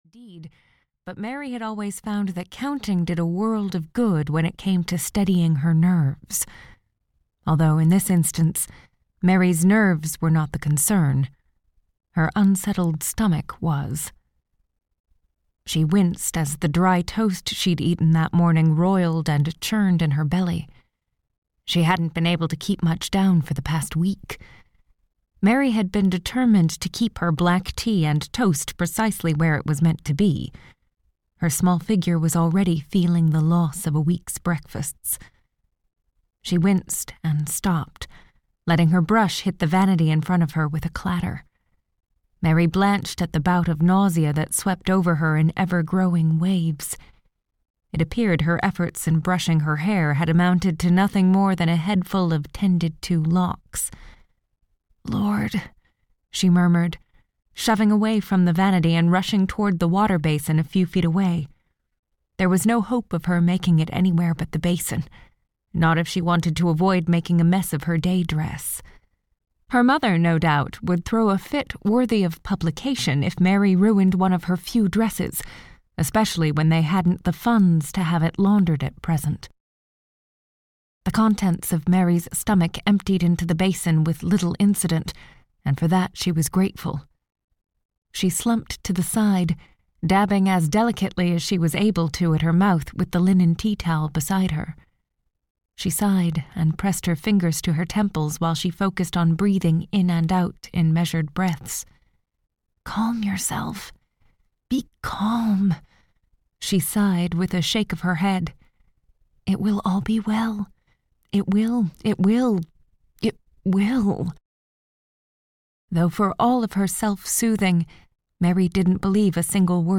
Leather and Lace (EN) audiokniha
Ukázka z knihy